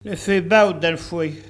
Locutions vernaculaires